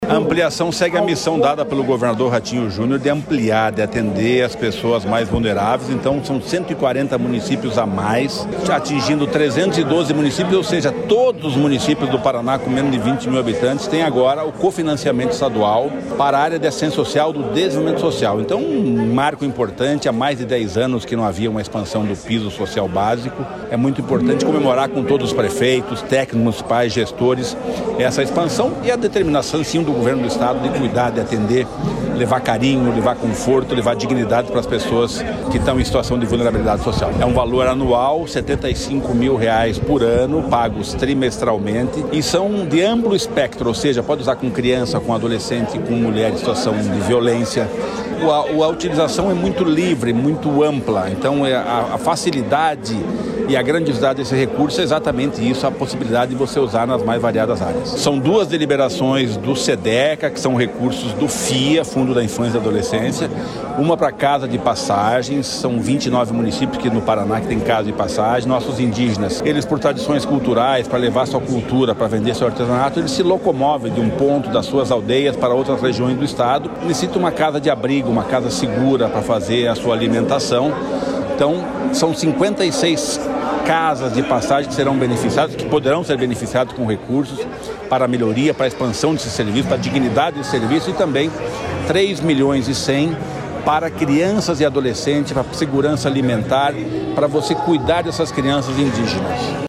Sonora do secretário de Desenvolvimento Social e Família, Rogério Carboni, sobre a ampliação dos investimentos em assistência social e reforço de ações em 140 municípios